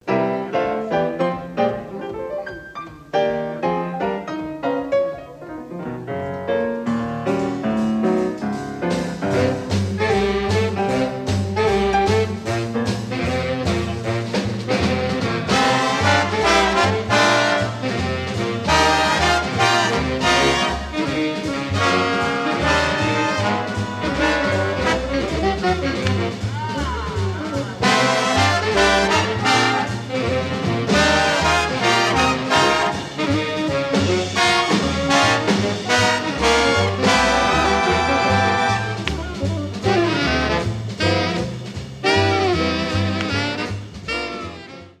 Helsinki 1963